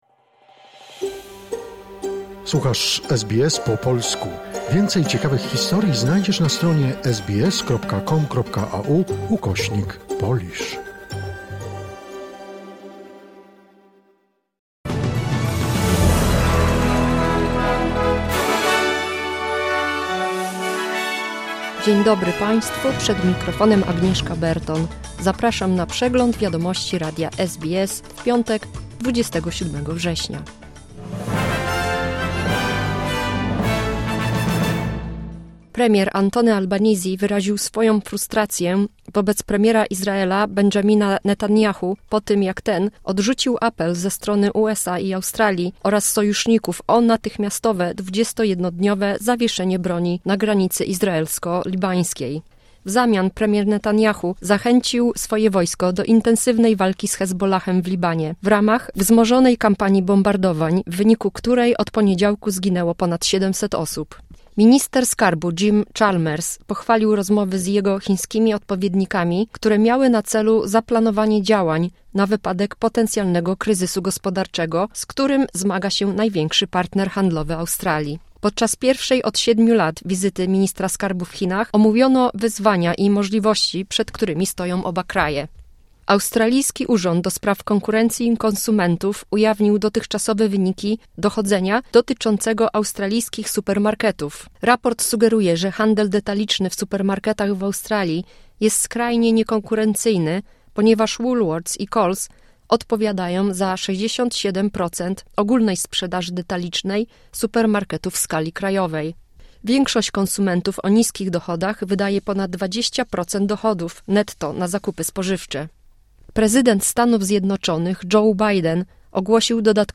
Skrót najważniejszych doniesień z Australii i ze świata, w opracowaniu polskiej redakcji SBS.